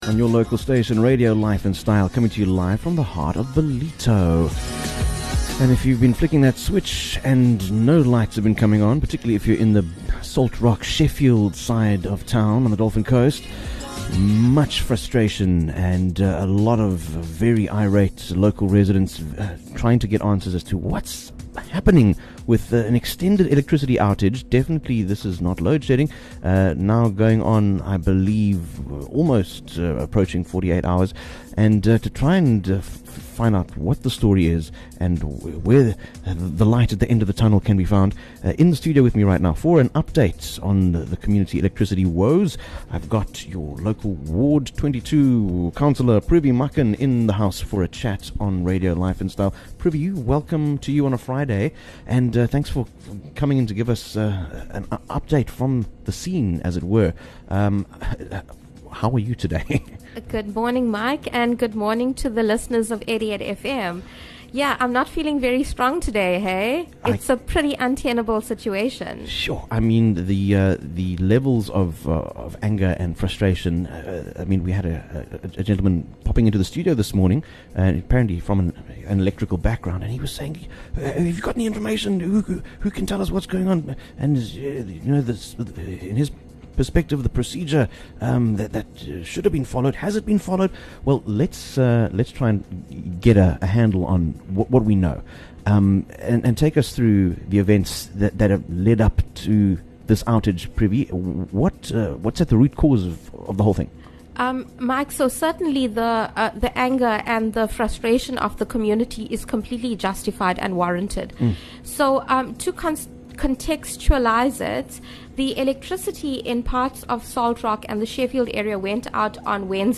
Ward Councillor Privi Makhan from Ward 22 (Sheffield / Salt Rock) provides a Friday morning update on the serious electricity outage affecting desperate residents in her KwaDukuza Municipal (KDM) ward. Privi unpacks the root cause of what caused the current electrical faults, why repairs have been delayed, and highlights frustrations with the current local municipal operating procedures and the poor level of communication from local municipal structures between technicians, contractors, and the public.